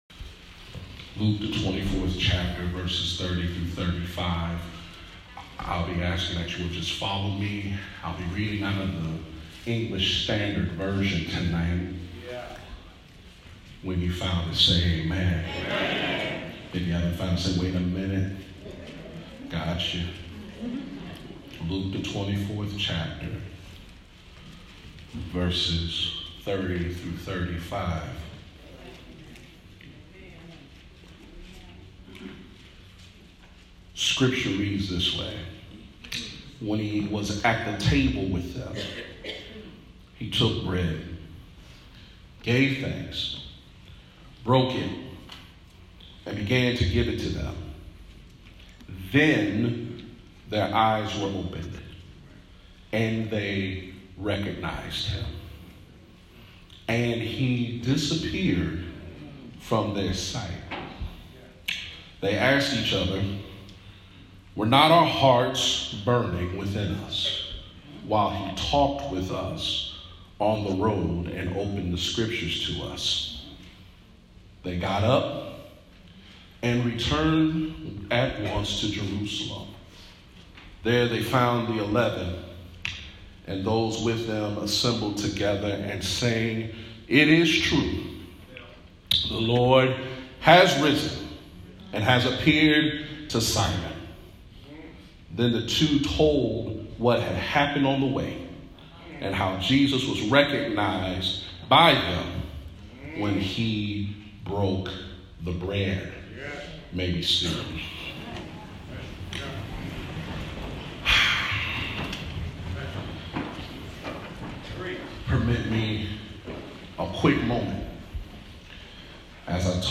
Communion Service at Mount Olivet Baptist Church – Wesley Church of Hope UMC